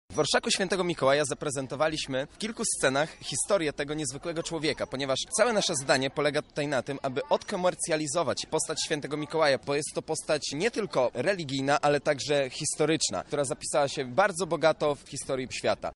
W trakcie przemarszu prezentowane były sceny z życia świętego przygotowane przez artystów z teatru Panopticum z domu kultury „Pod Akacją”. O inscenizacji mówi jeden z uczestników